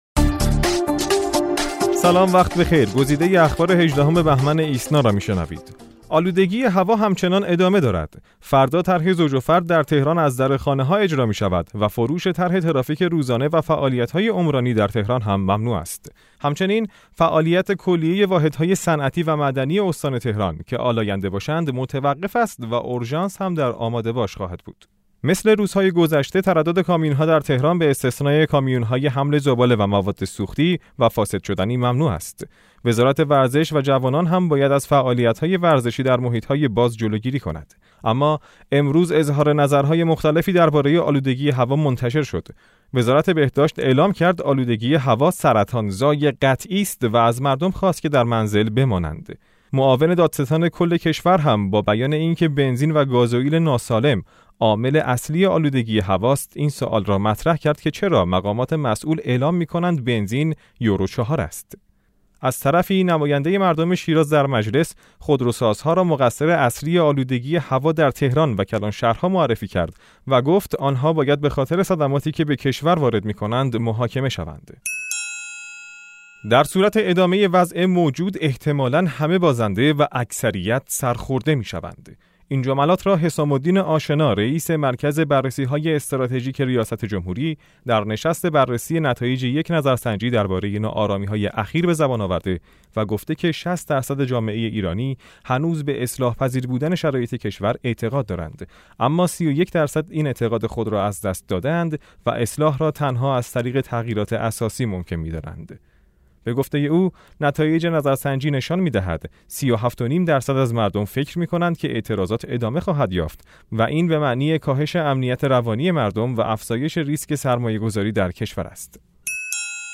صوت / بسته خبری ۱۸ بهمن ۹۶